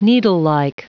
Prononciation du mot needlelike en anglais (fichier audio)